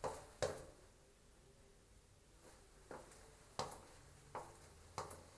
描述：吸血鬼 唱得那么可爱！
标签： 生物 尖叫 怪异 怪物 僵尸 Horro R 吸血鬼 爬行 噪音 可怕
声道立体声